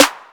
Down Grade Snare.wav